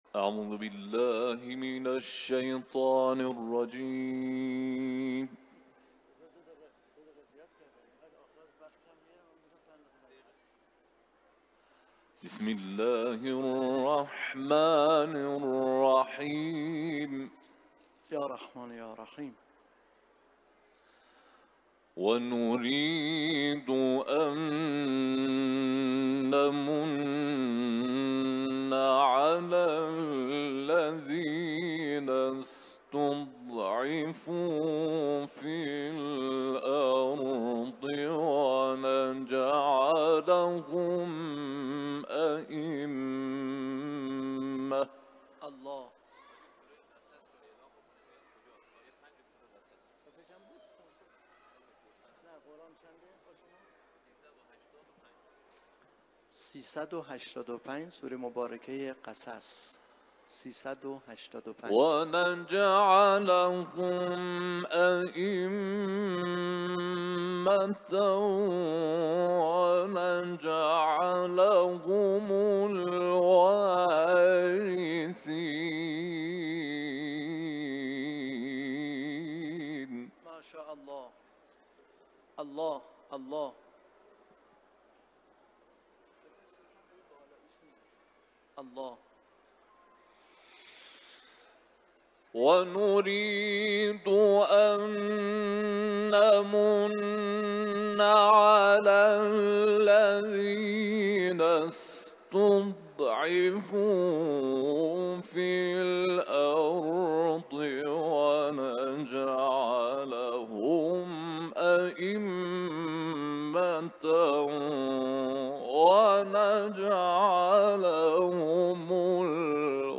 تلاوت‌